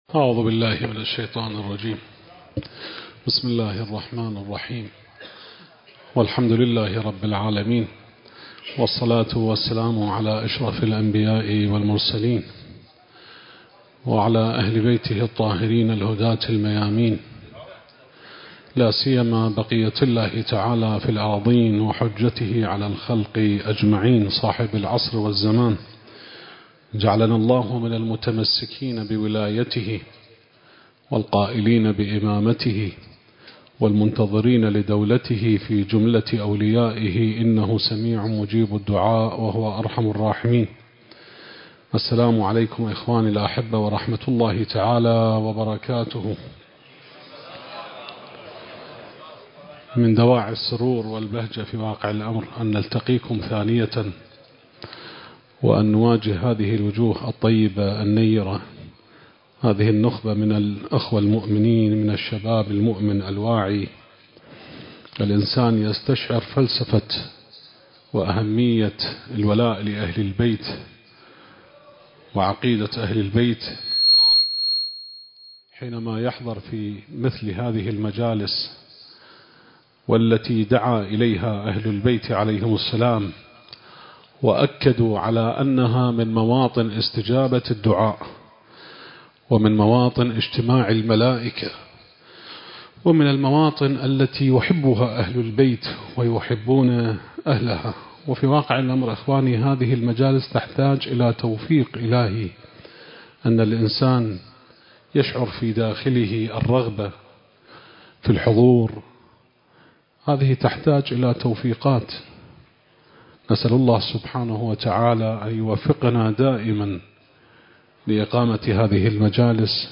المكان: جامع وحسينية أهل البيت (عليهم السلام) / بغداد التاريخ: 2025